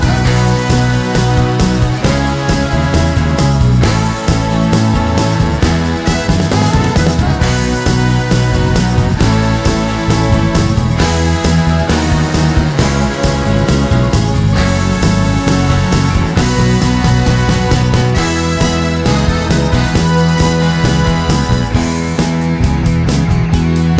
no Backing Vocals Jazz